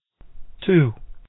man2.mp3